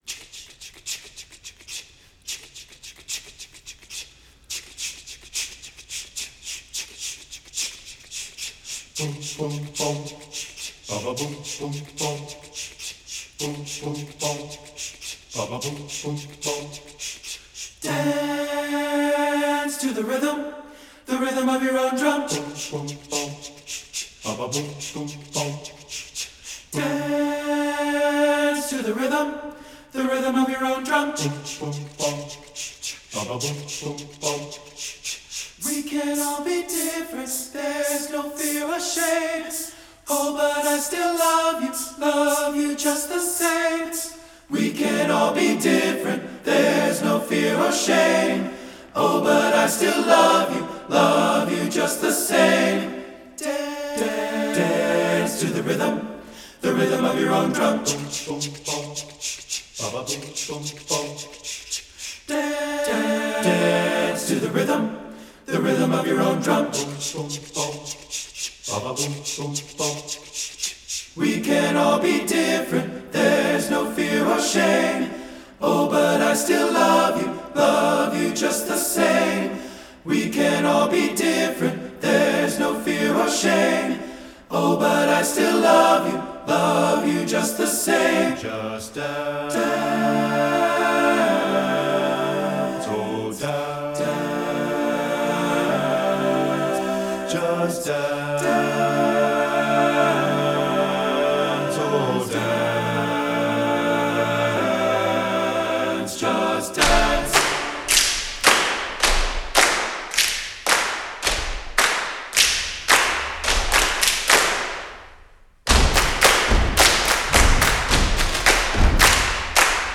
Voicing: TTB a cappella